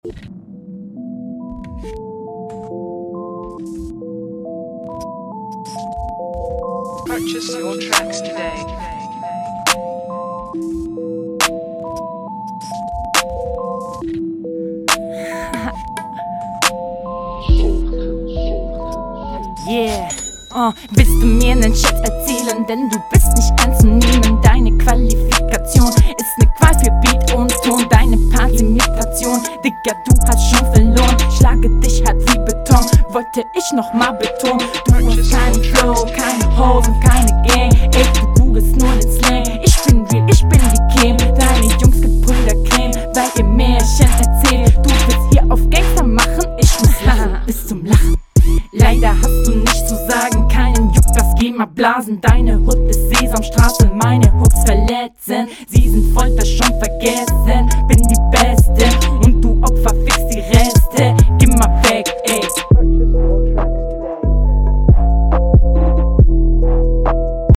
Abmische ist noch nicht ausgeprägt.
Flow: Relativ monoton aber bis auf den Schluss recht sauber.